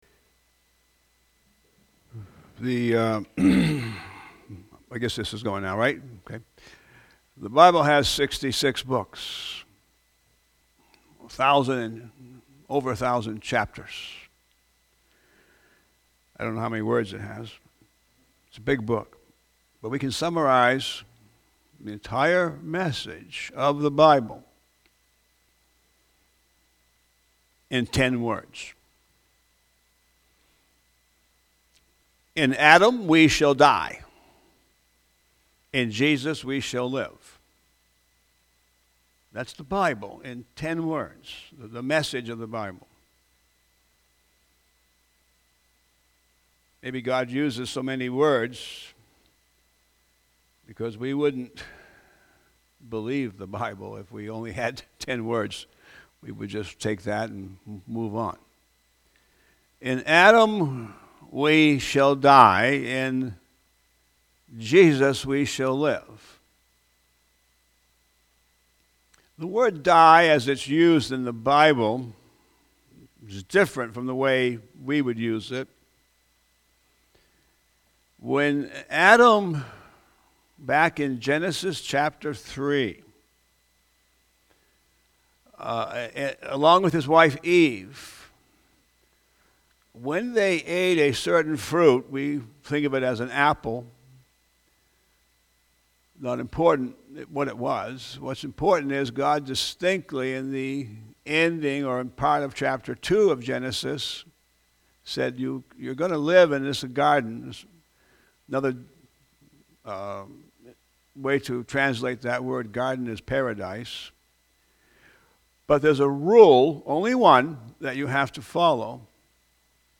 Passage: 1 Corinthians 15:22 Service Type: Sunday AM